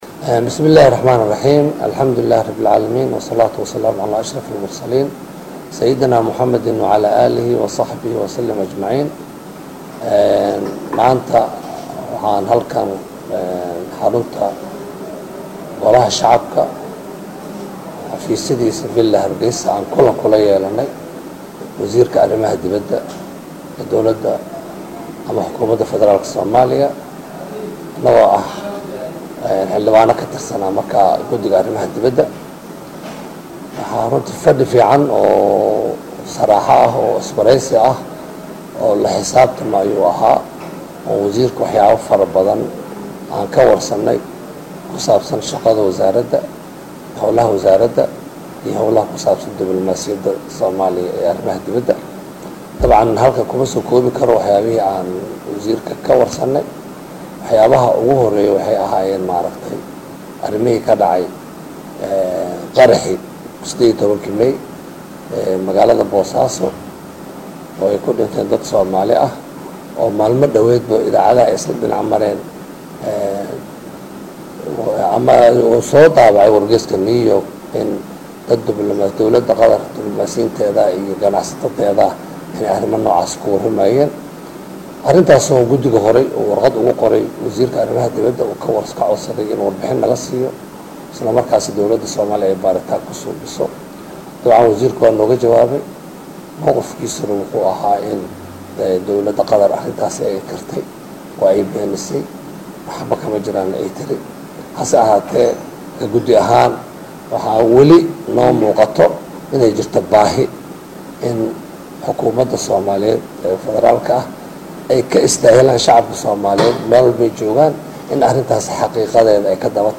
Guddoomiyaha guddiga arrimaha dibadda ee Golaha Shacabka Xildhibaan Cabdulqaadir Cosoble Cali oo kulanka shir guddoominaayay ayaa ka warbixiyay qodobadii ay kala hadleen wasiirka iyo guddiga.